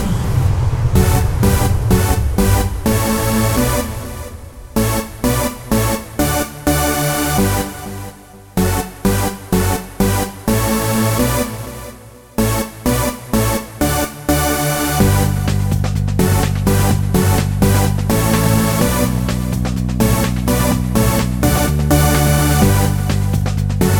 no Backing Vocals R'n'B / Hip Hop 3:58 Buy £1.50